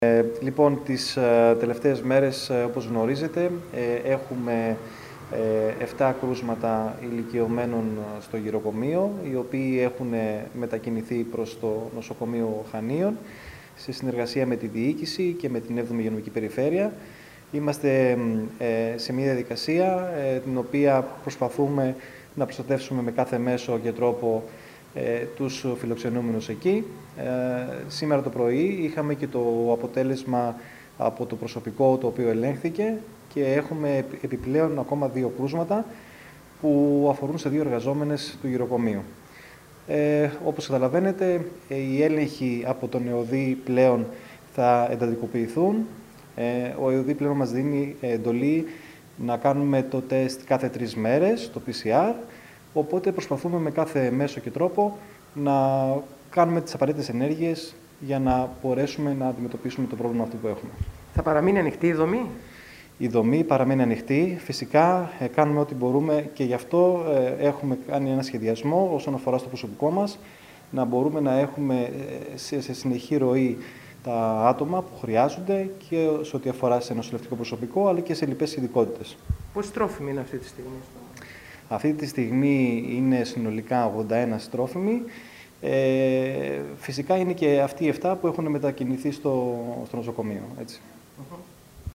Εξάλλου ο Γενικός Γραμματέας του Δήμου, Γιώργος Φραγκιαδάκης, δήλωσε στην ΕΡΤ, ότι θα εντατικοποιηθούν οι έλεγχοι στο Γηροκομείο, ενώ η δομή θα παραμείνει ανοιχτή :